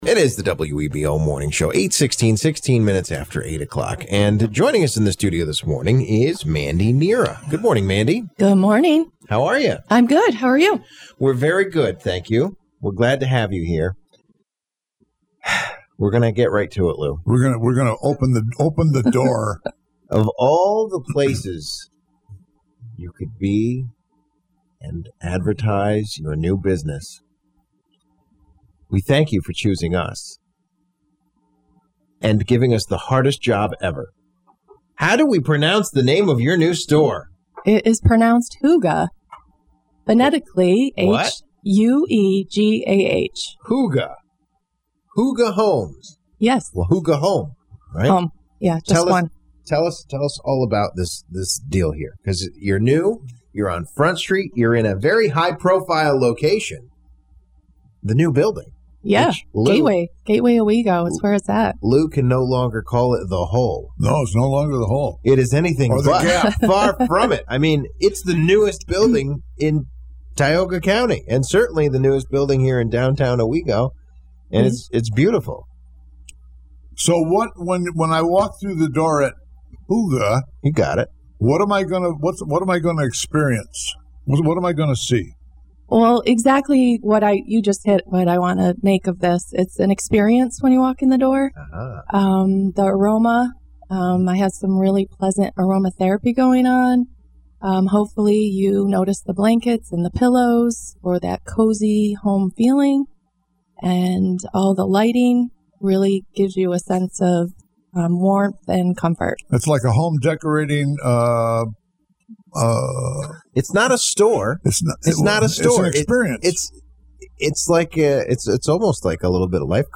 Hygge-Home-Morning-Show-Interview.mp3